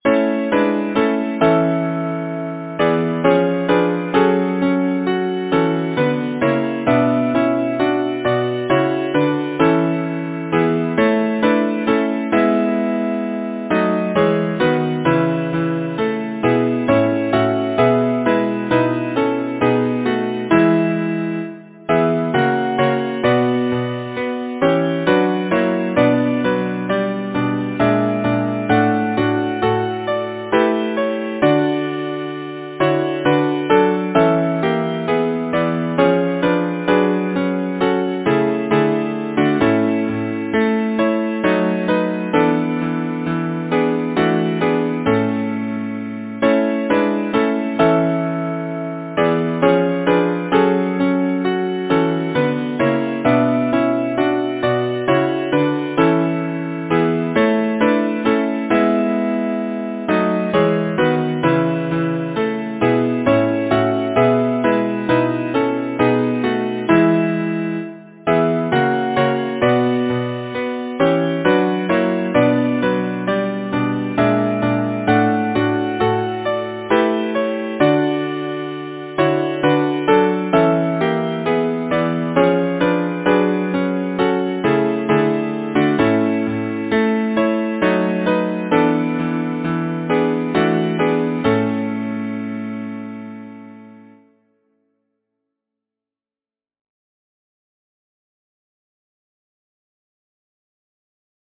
Title: The clouds that wrap the setting sun Composer: Samuel Reay Lyricist: John Keble Number of voices: 4vv Voicing: SATB Genre: Secular, Partsong
Language: English Instruments: A cappella